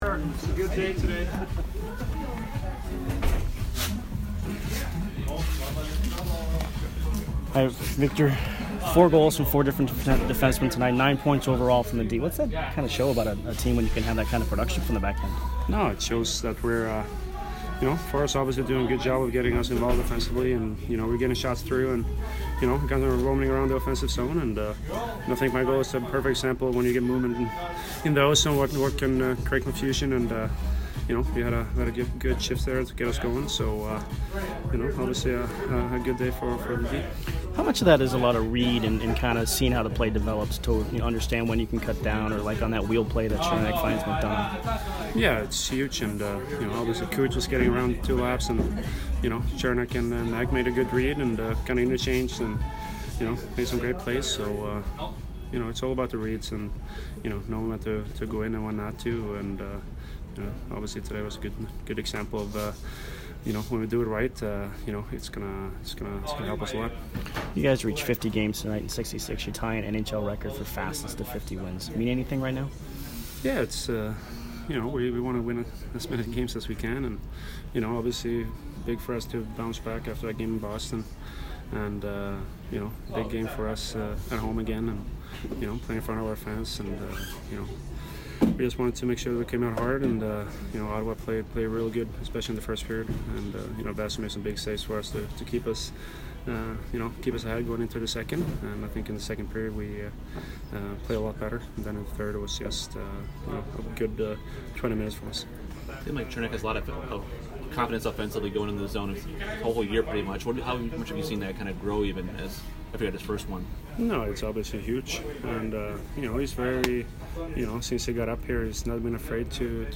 Victor Hedman post-game 3/2